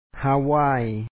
háawàay Hawaii